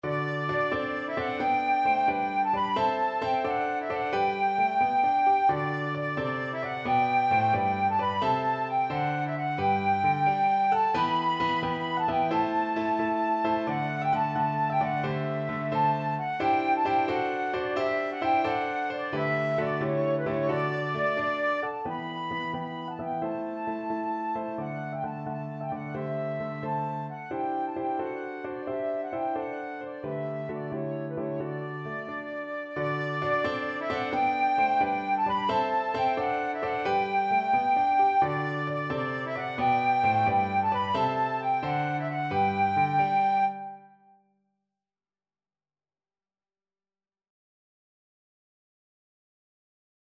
Flute version
6/8 (View more 6/8 Music)
Traditional (View more Traditional Flute Music)